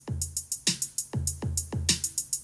Our drum machine will support three different sounds: hi-hat, snare, and toms.
We will give BPM a default value of 100.